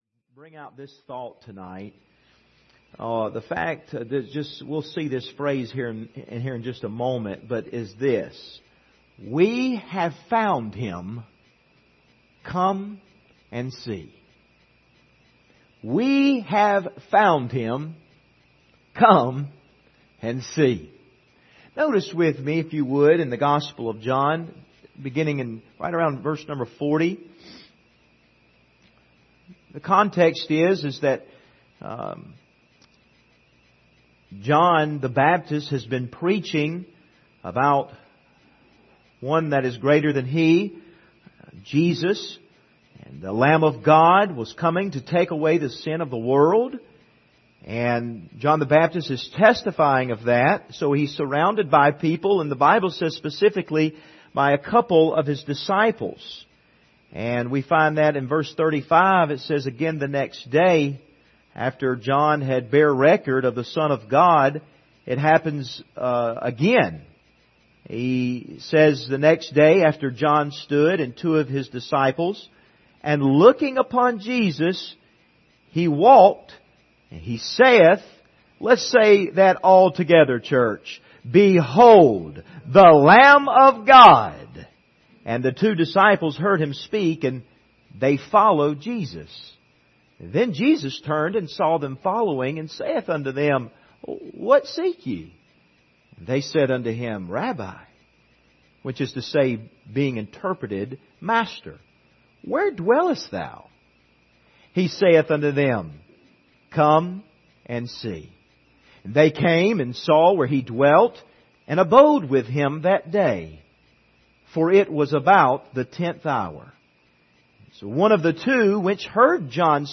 Passage: John 1:35-50 Service Type: Sunday Evening